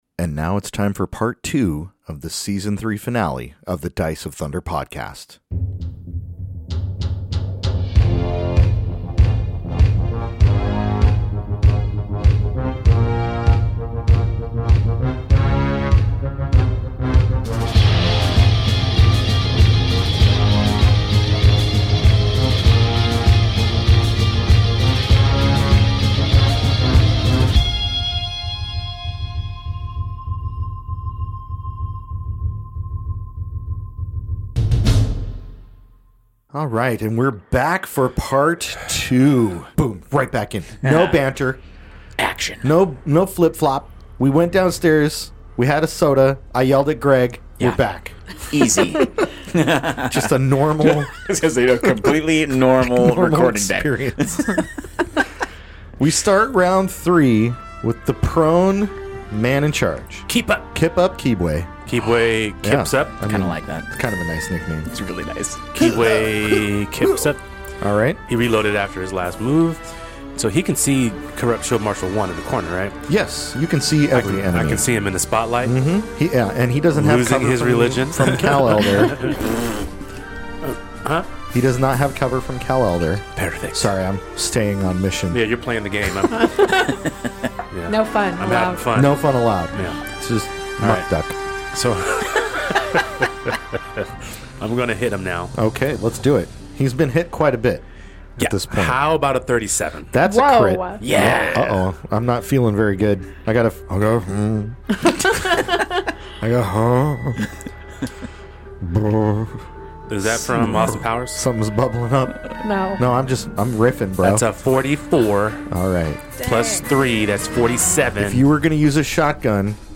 A Pathfinder 2nd Edition actual play podcast suitable for all ages! Join us as we play through the Outlaws of Alkenstar adventure path published by Paizo Inc. as part of the Pathfinder 2nd Edition tabletop role-playing game.
The show consists of new players and an experienced GM.